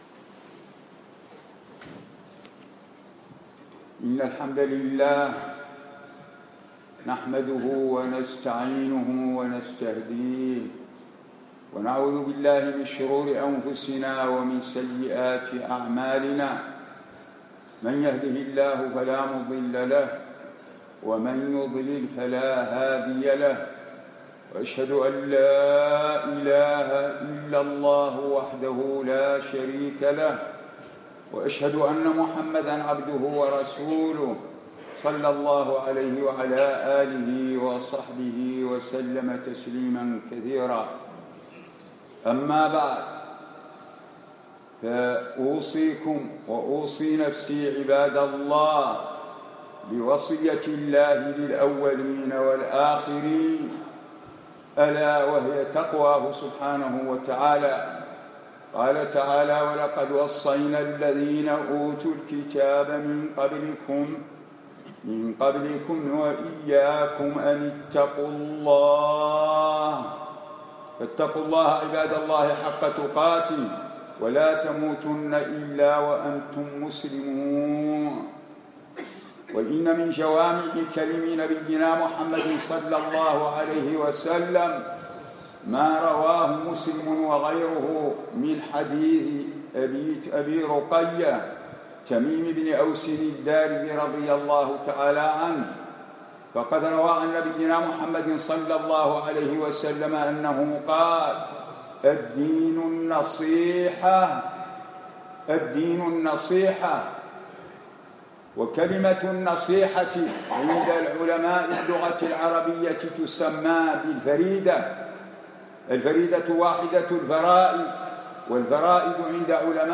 الدين النصيحة - خطبة - دروس الكويت
الجمعة 24 جمادى الأولى 1437 الموافق 4 3 2016 مسجد عبدالله بن الأرقم الجهراء